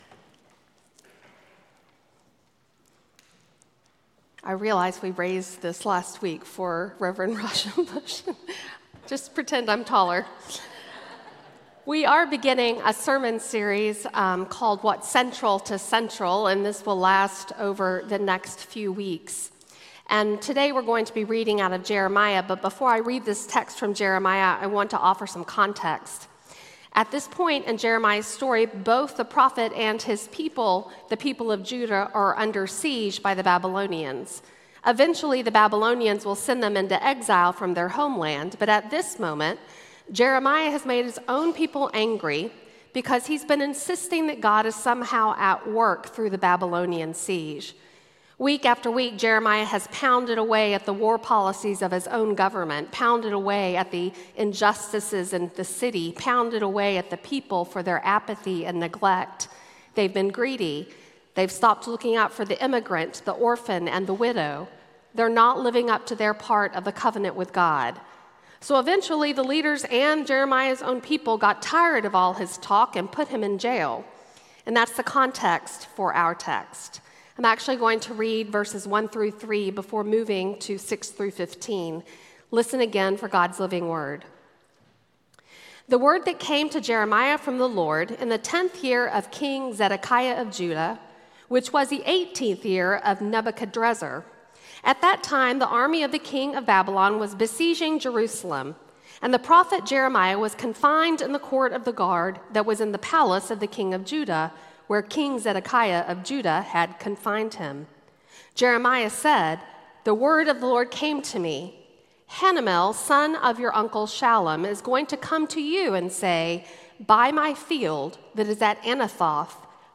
Sermon Audio:
Passage: Psalm 91, Jeremiah 32:6-15 Service Type: Sunday